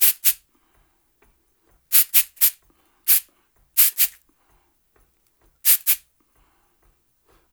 128-SHAK1.wav